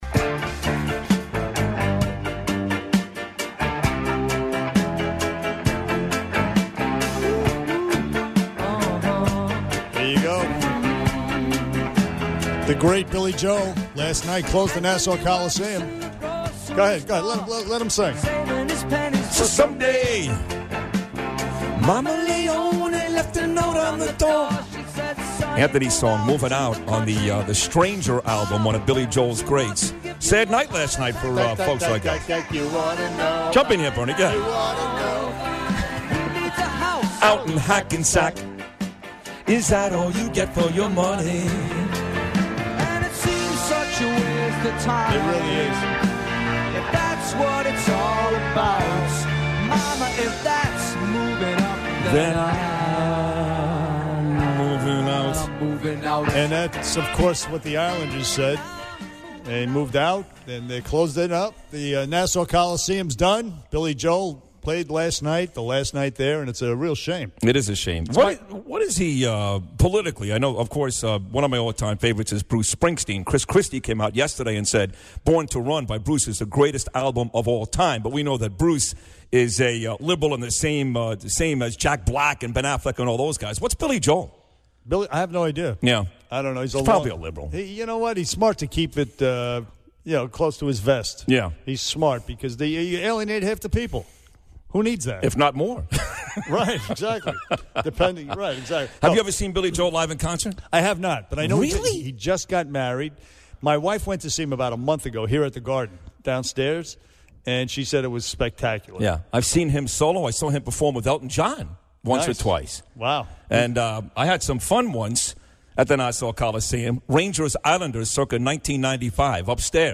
They discuss the upcoming debate and more, plus are joined by Congressman Peter King in studio